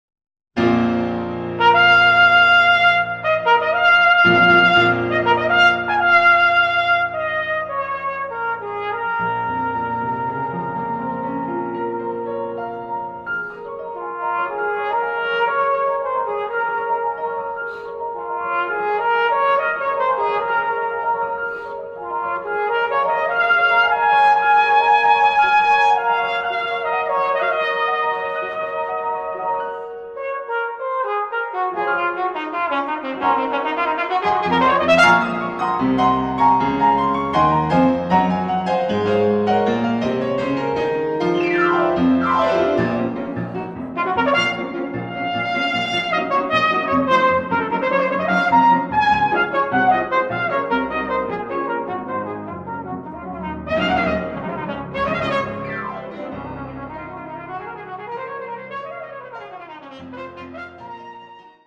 Besetzung: Instrumentalnoten für Trompete